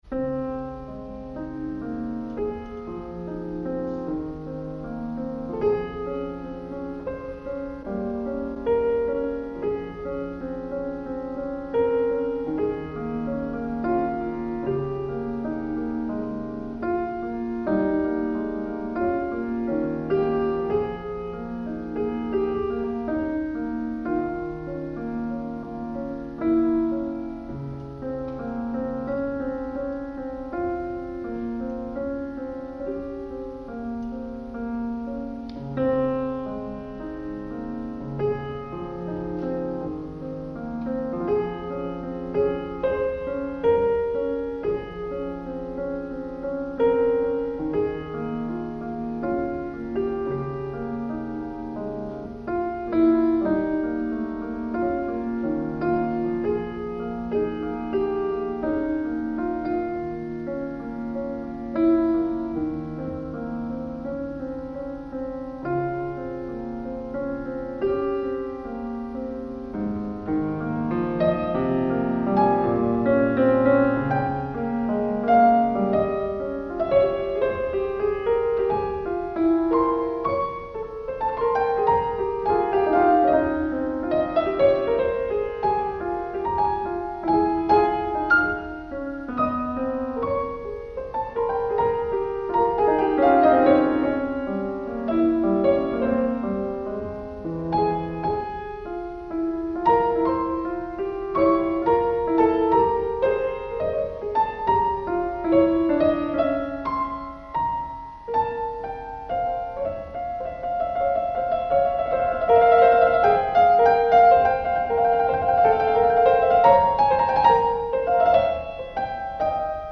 for solo piano